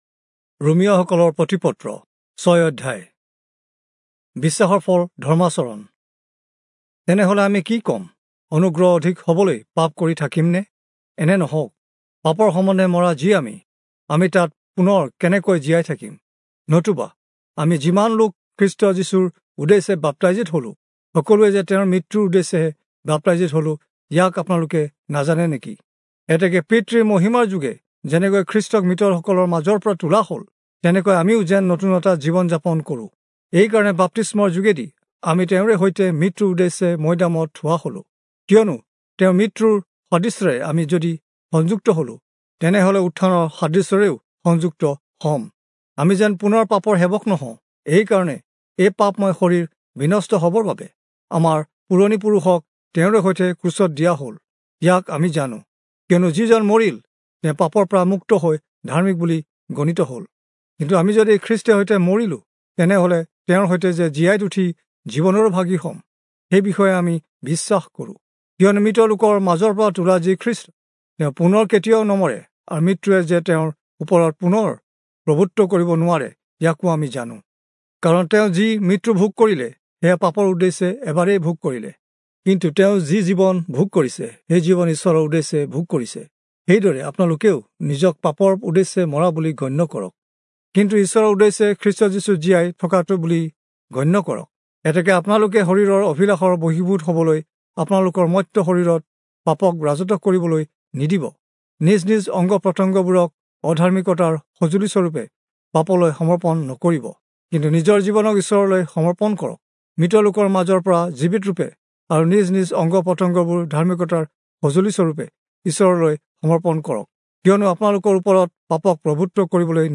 Assamese Audio Bible - Romans 14 in Mhb bible version